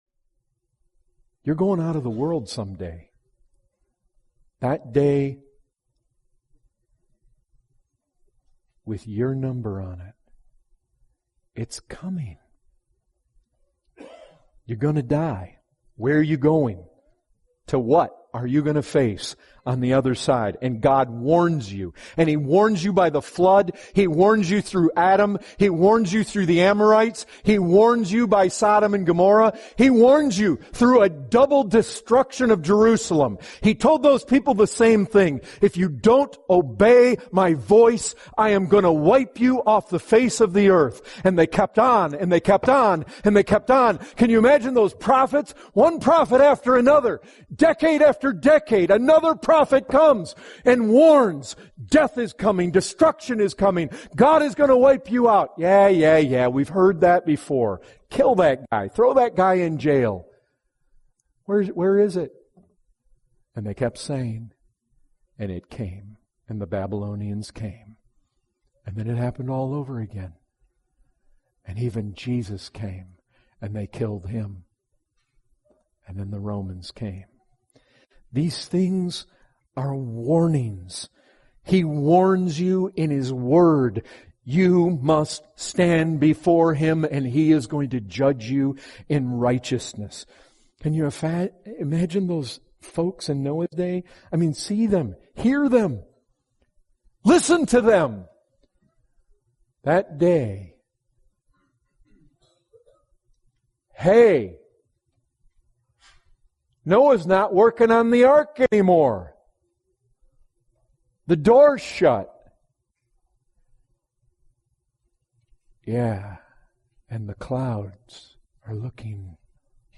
The excerpt is taken from the full sermon, “ Dying You Shall Die “.